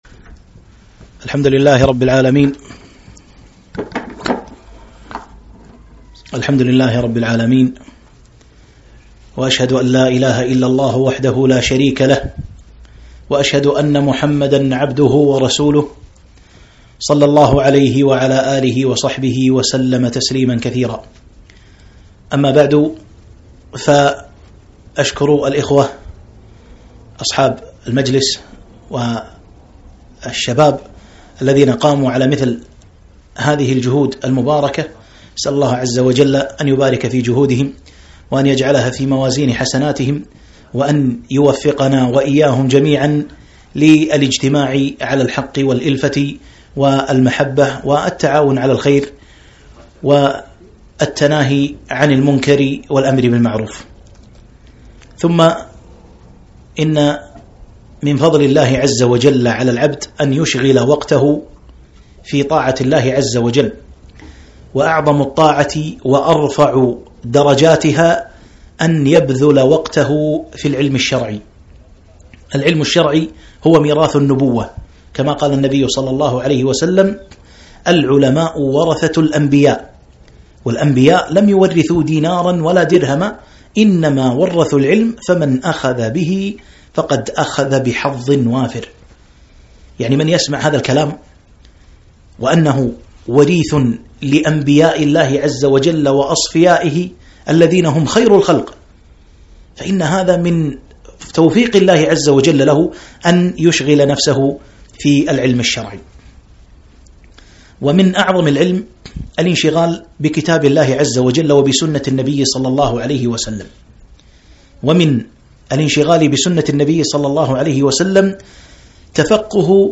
محاضرة - وقفات مع حديث ( ماذئبان جائعان )